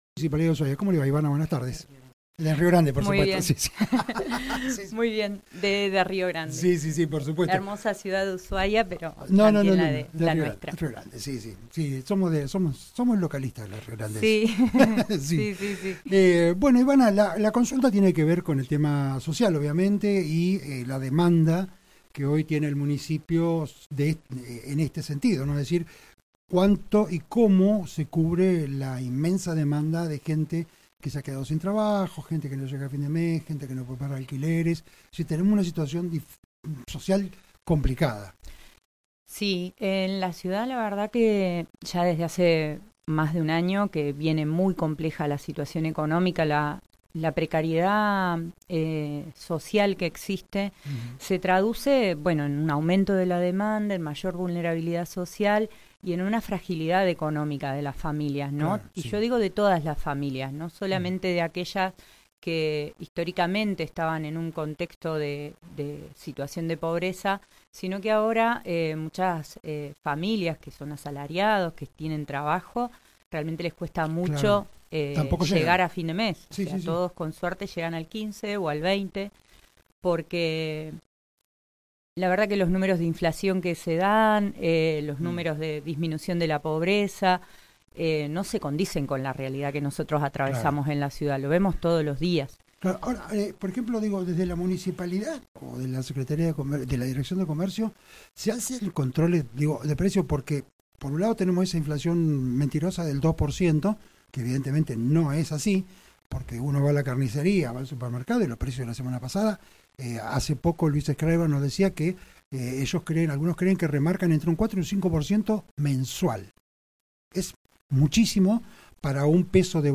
En dialogo con Resumen Económico la Secretaria de Desarrollo socia, Genero y Diversidad, señaló que en la ciudad desde mas de un año la situación económica, la precariedad social que existe , se traduce en un aumento de la demanda, mayor vulnerabilidad social y en una fragilidad económica de las familias y yo digo de todas las familias, no solamente de aquellas que, históricamente están en un contexto de situación de pobreza, sino que ahora, muchas familias asalariadas, con trabajo, tambien les cuesta mucho llegar a fin de mes».